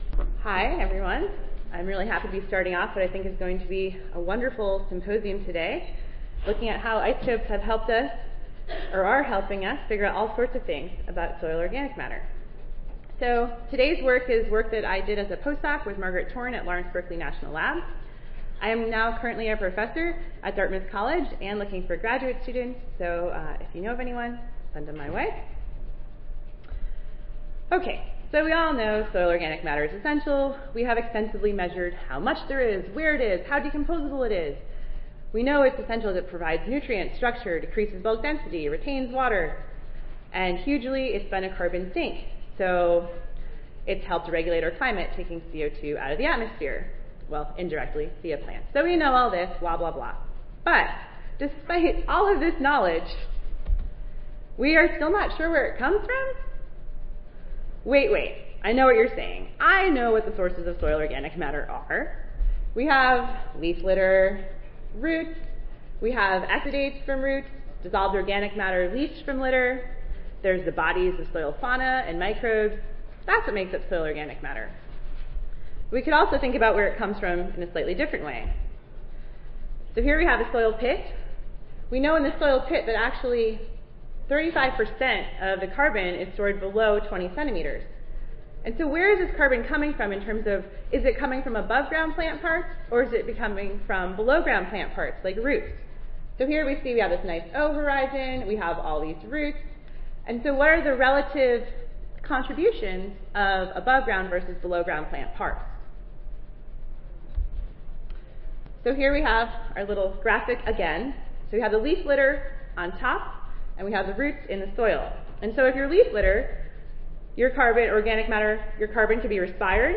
Lawrence Berkeley National Laboratory and UC Berkeley Audio File Recorded Presentation